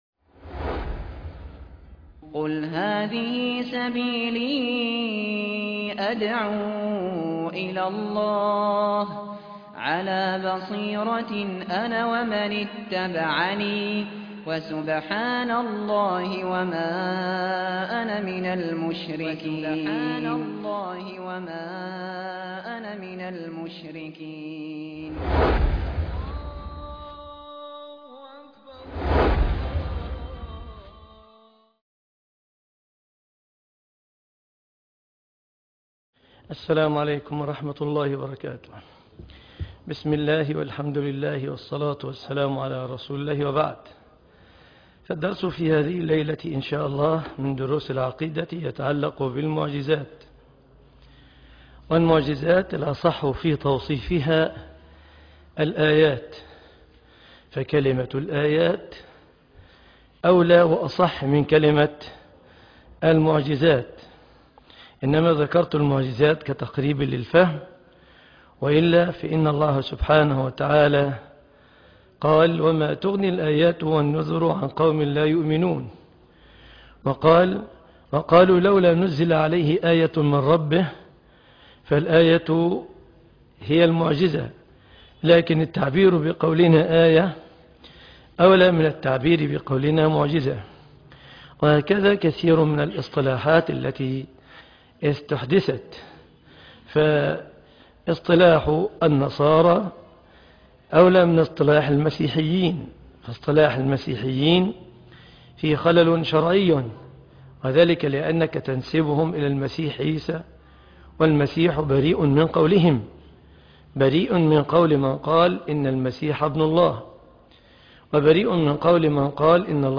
دروس العقيدة - مجمع التوحيد بالمنصورة